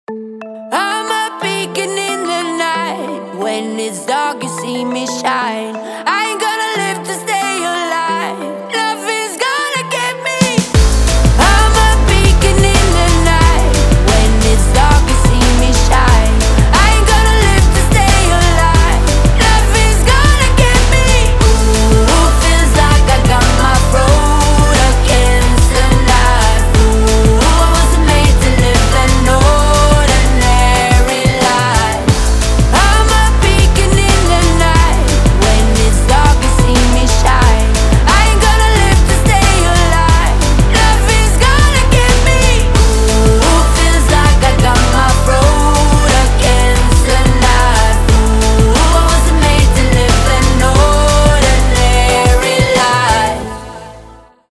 • Качество: 320, Stereo
громкие
мощные
женский вокал